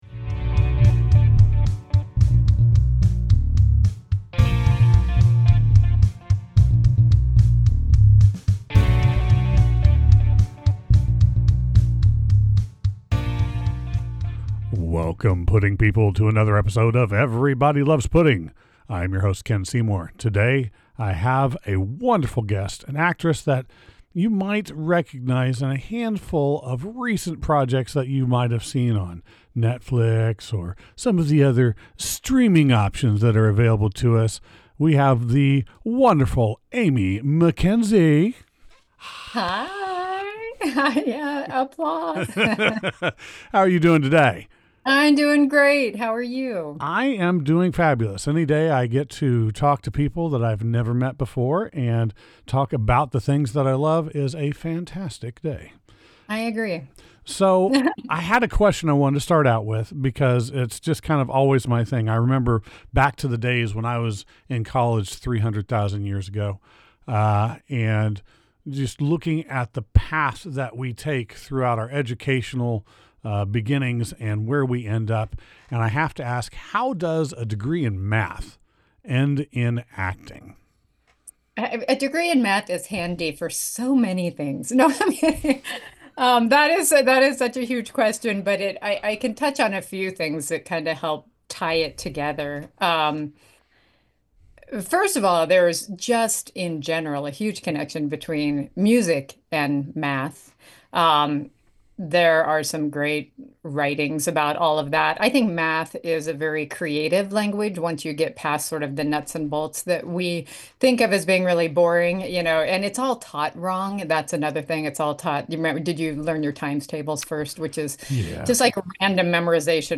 Everybody Loves Pudding 7.26: Interview